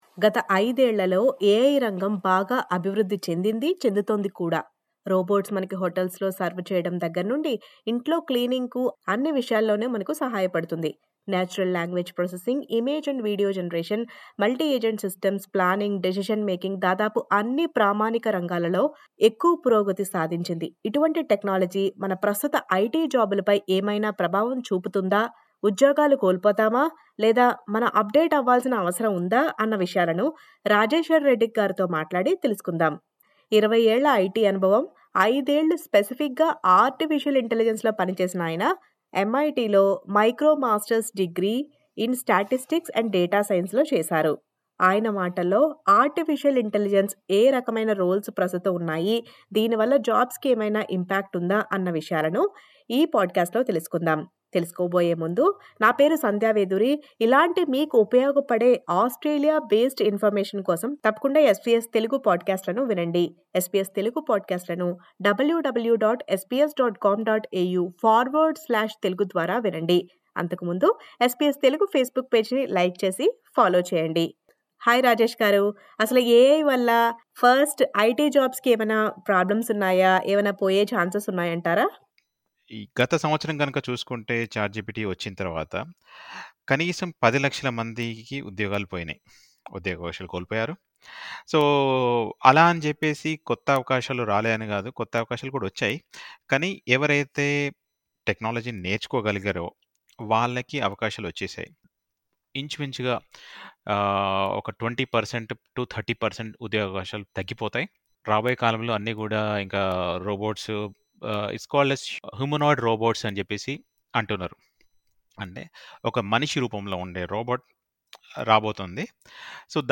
SBS Telugu